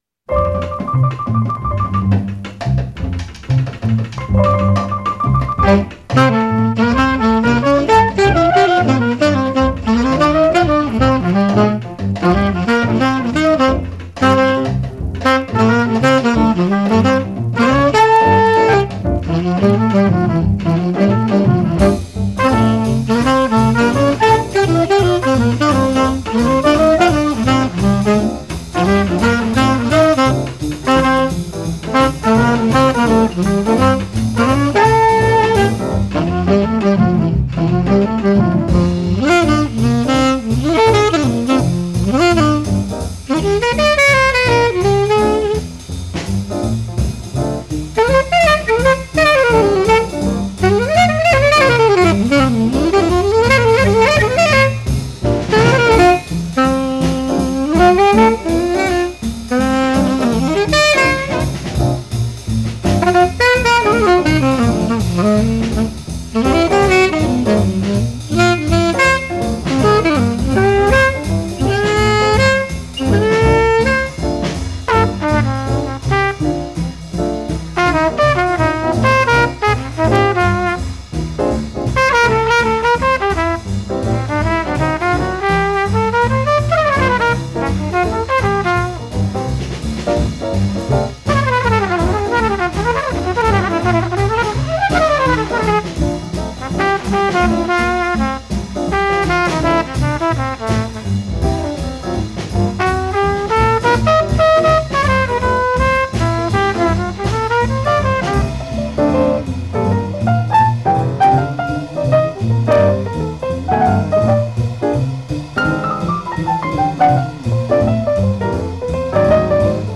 Musikhistoriegruppe_B-1: Bebop        GRUPPERAPPORT